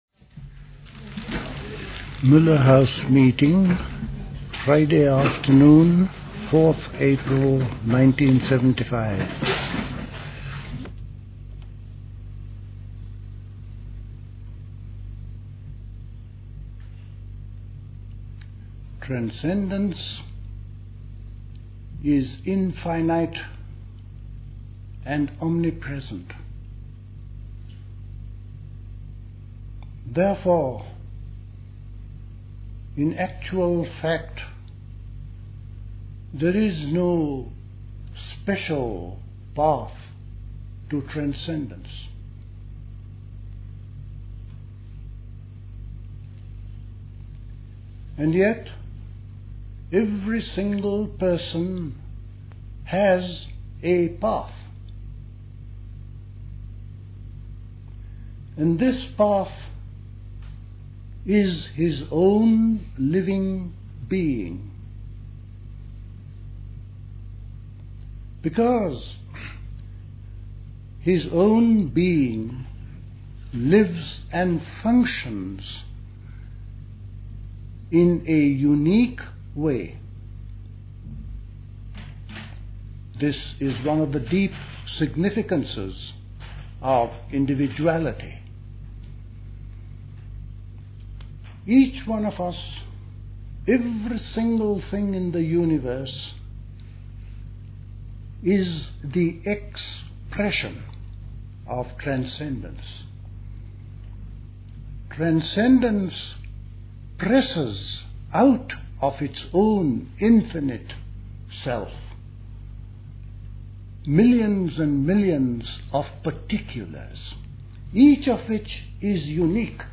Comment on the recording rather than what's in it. Recorded at the 1975 Elmau Spring School.